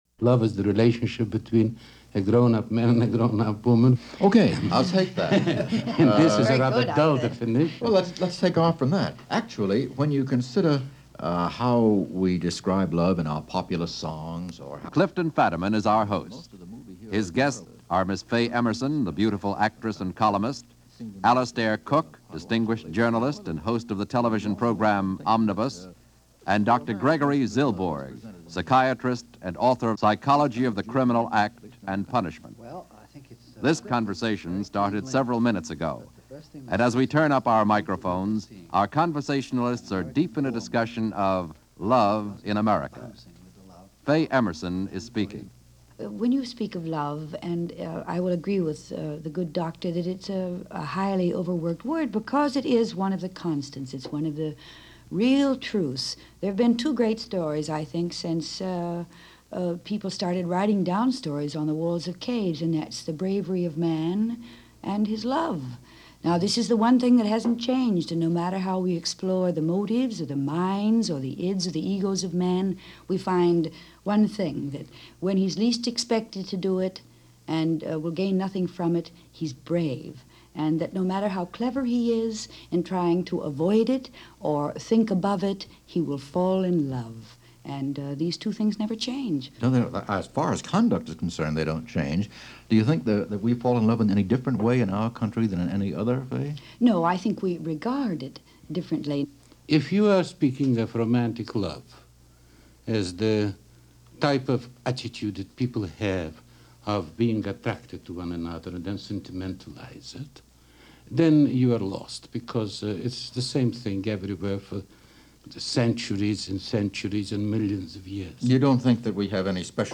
So the conversation, in this case a conversation broadcast as part of the radio series Conversation, concentrated on the whole concept of Love and what it meant to America, almost 70 years ago.